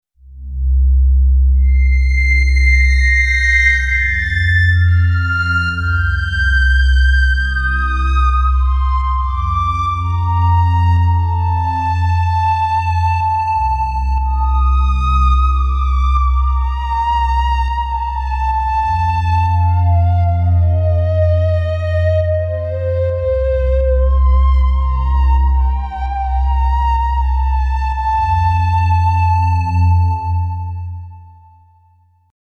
Everything was played right on the AstroLab, and there was no extra sound processing done.
Tirun is a very deep and atmospheric pad, and the AstroLab delivers its earth-shattering rumbles and piercing highs with ease.